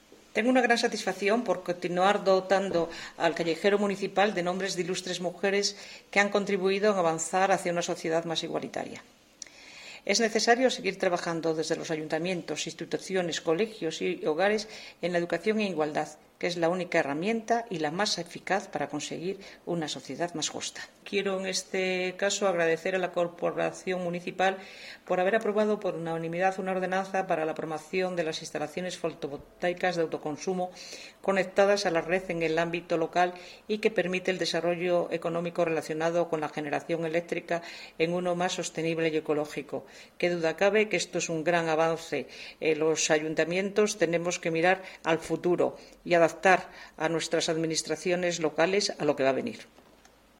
Audio de María Teresa Noceda Llano